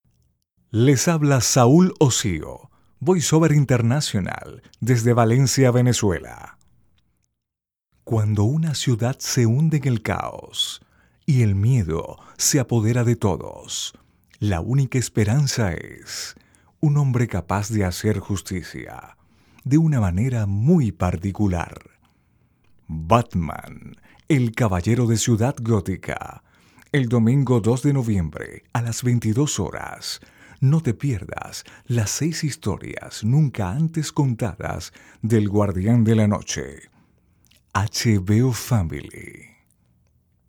Locutor profesional con experiencia de 10 años en conduccion produccion de programas de radio.Experiencia en narracion de documentales, comerciales e identificacion para agencias de publicidad.
spanisch Südamerika
Sprechprobe: Industrie (Muttersprache):